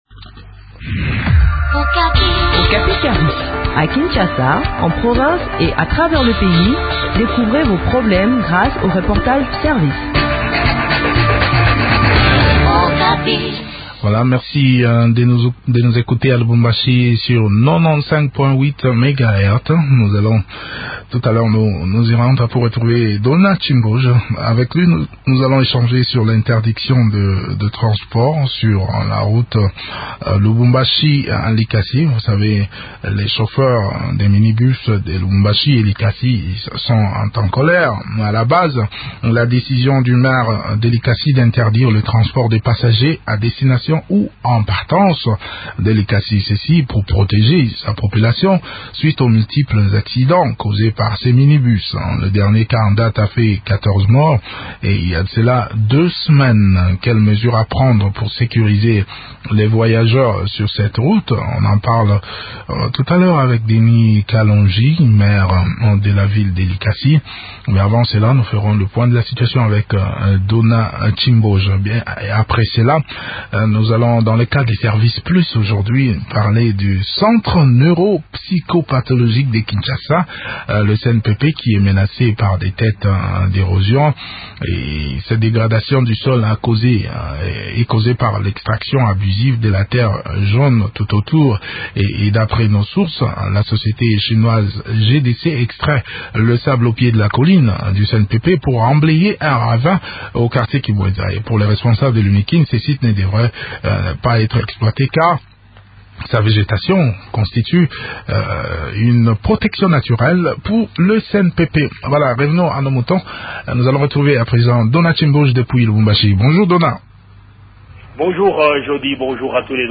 s’entretient sur le sujet avec Denis Kalondji, maire de la ville de Likasi.